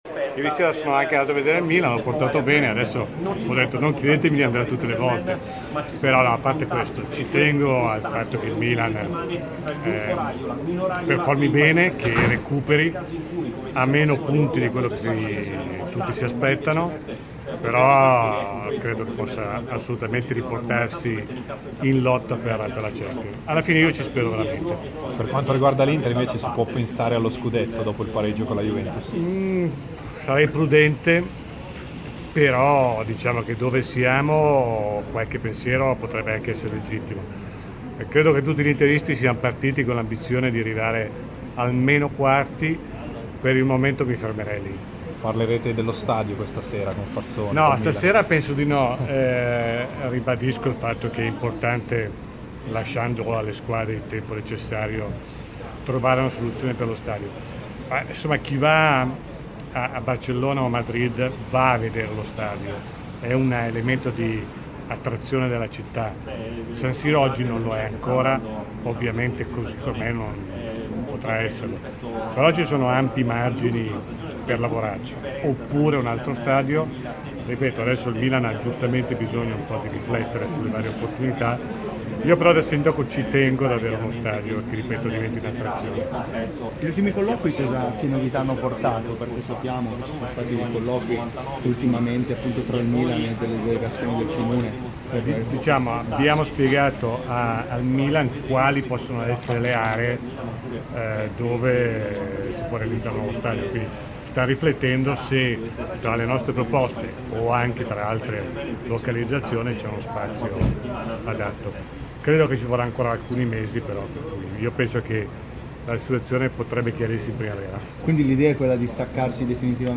è intervenuto in diretta a Stadio Aperto, trasmissione di TMW Radio